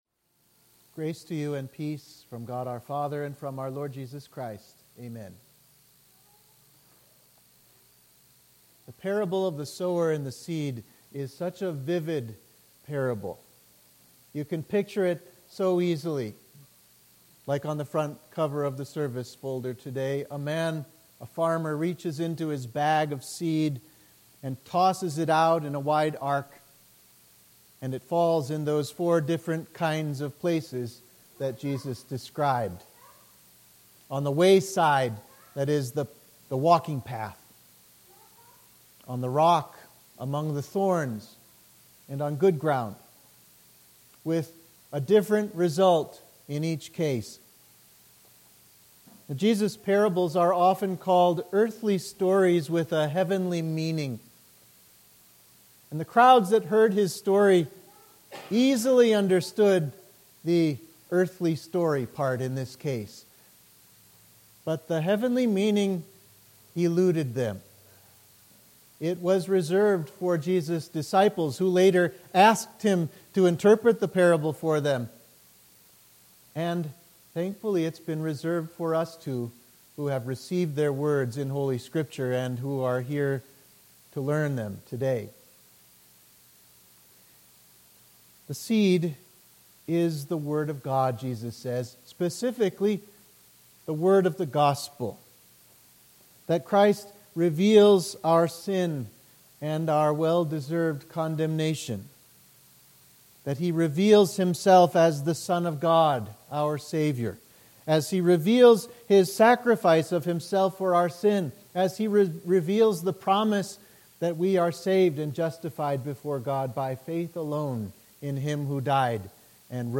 Sermon for Sexagesima